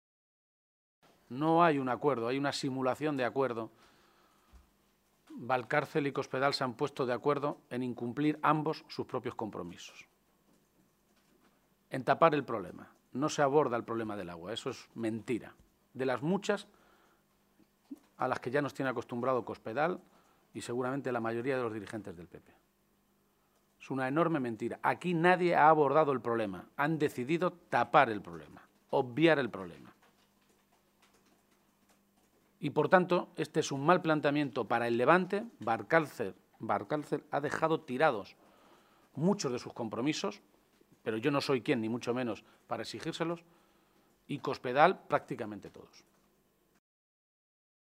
Emiliano García-Page, Secretario General del PSOE de Castilla-La Mancha
Cortes de audio de la rueda de prensa